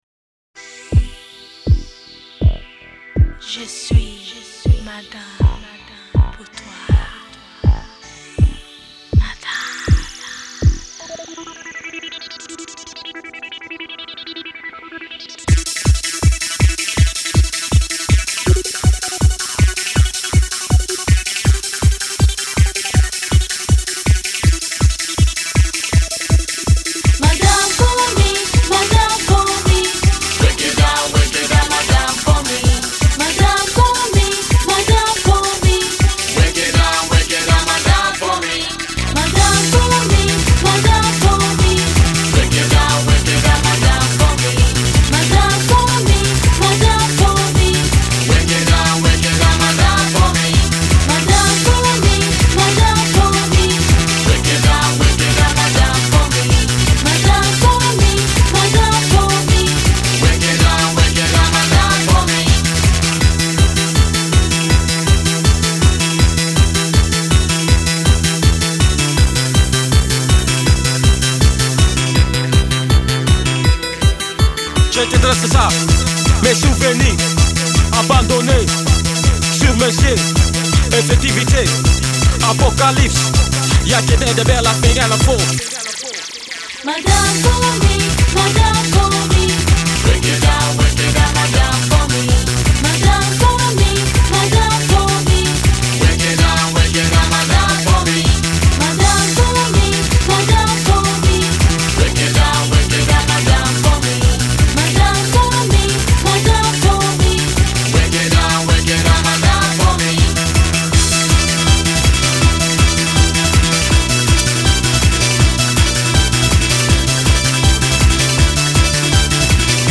хорошая песня о любви